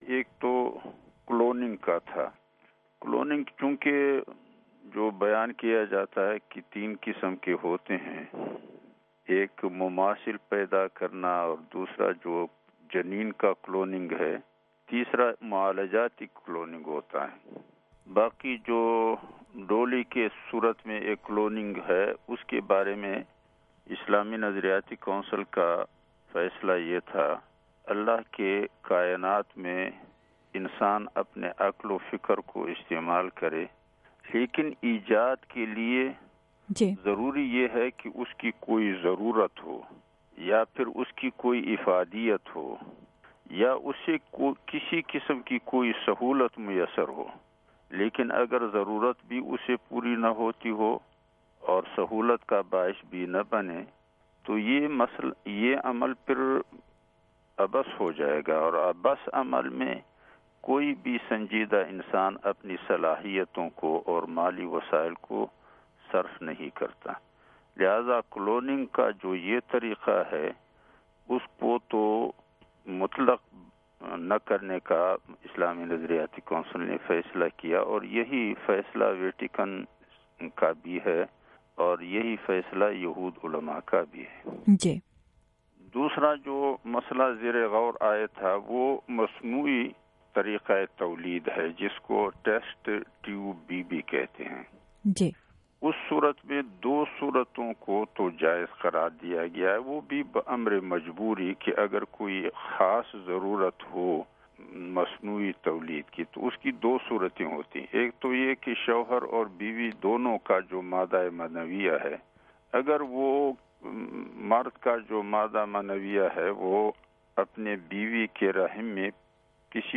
مصنوعی طریقہٴتولید، اسلامی نظریاتی کونسل کے سربراہ کا انٹرویو